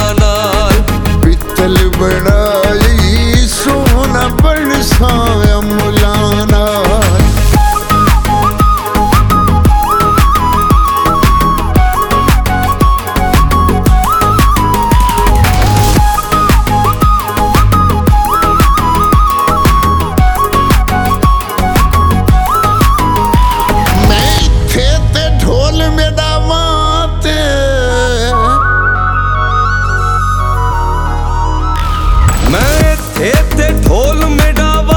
Жанр: Поп / Инди
# Indian Pop